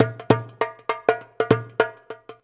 100DJEMB27.wav